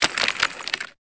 Cri de Crabicoque dans Pokémon Épée et Bouclier.